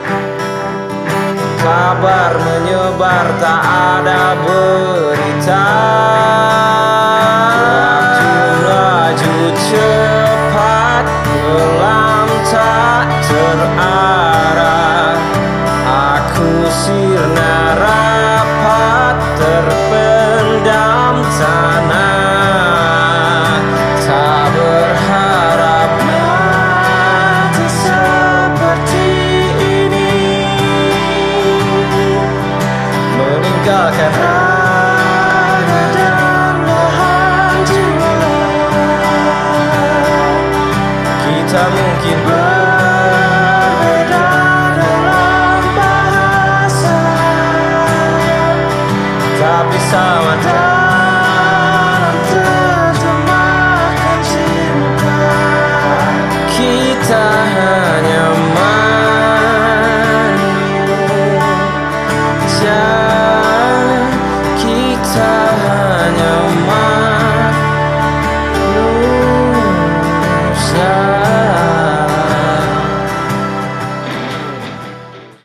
single ini bernuansa ballad